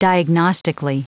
Prononciation du mot diagnostically en anglais (fichier audio)
Prononciation du mot : diagnostically